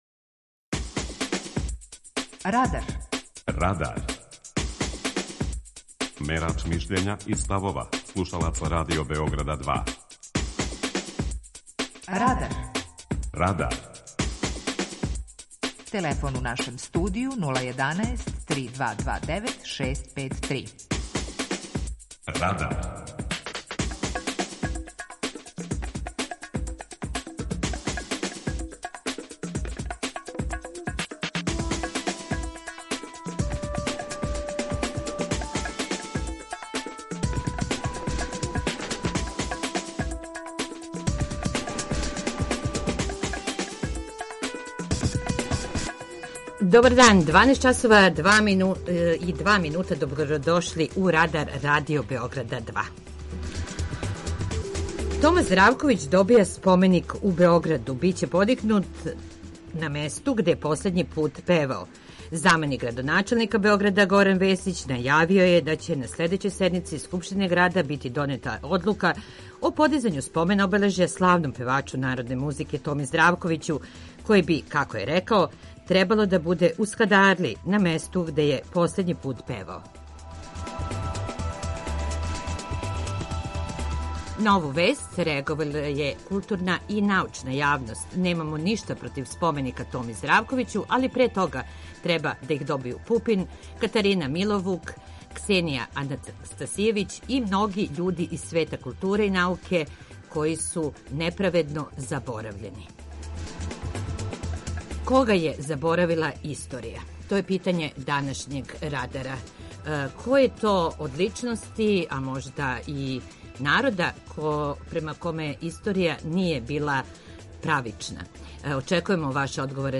Питање Радара: Према коме је историја била неправеднa? преузми : 19.17 MB Радар Autor: Група аутора У емисији „Радар", гости и слушаоци разговарају о актуелним темама из друштвеног и културног живота.